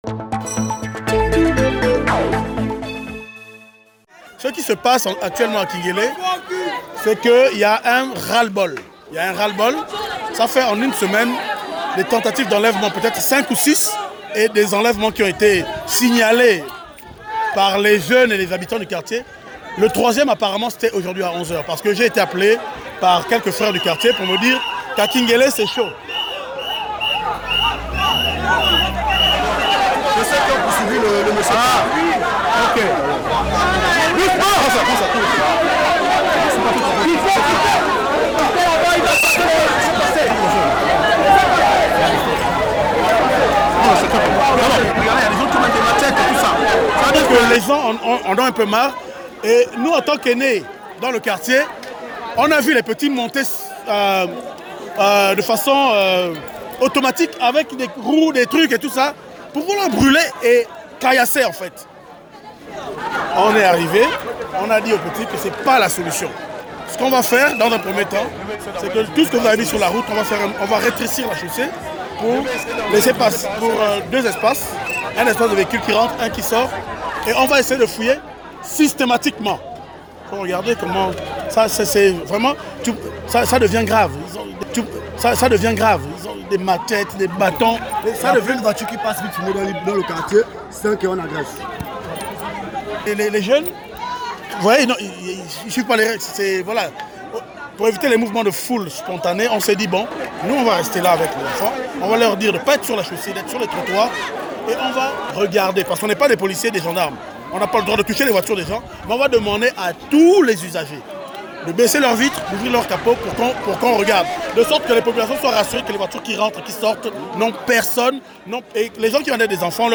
Radio TAMTAM AFRICA GABON : ENLÈVEMENTS D'ENFANTS Reportage -RadioTamTam 25 janvier 2020